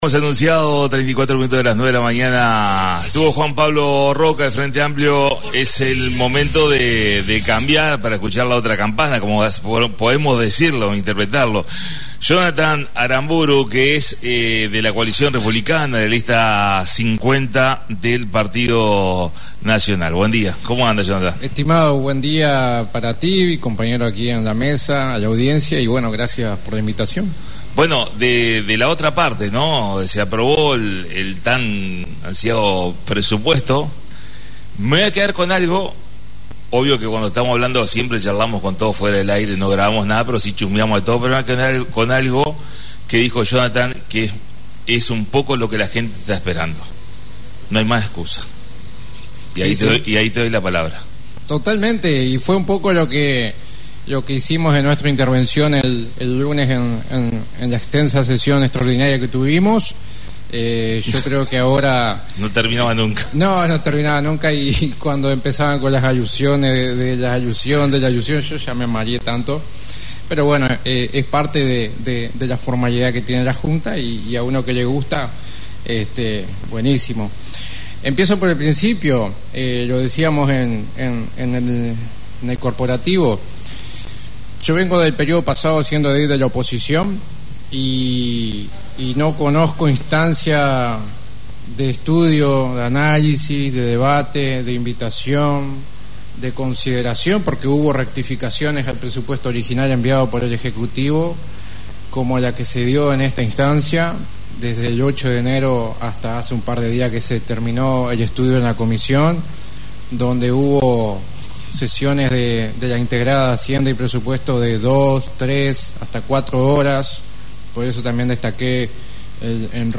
La visita a la Radio del Profesor Jonnathan Aramburu Edil Coalición Republicana